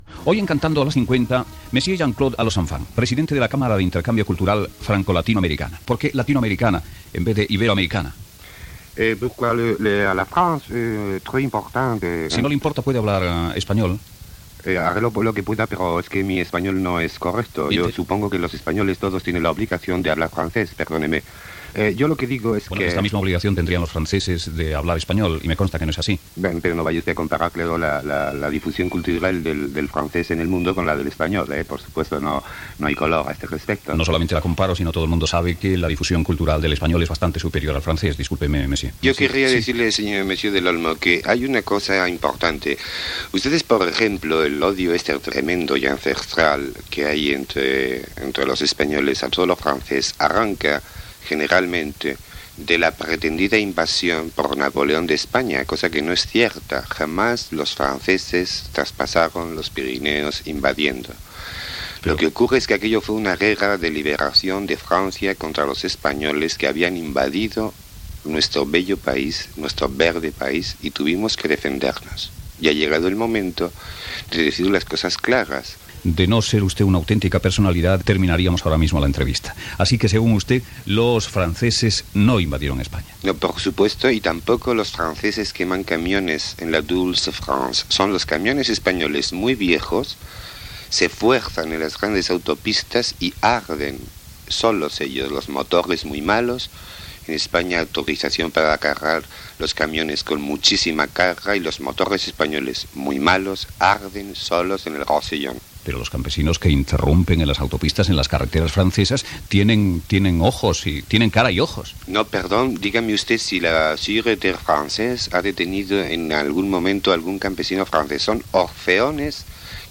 Info-entreteniment
Fragment extret del programa "Audios para recordar" de Radio 5 emès el 23 de desembre del 2013.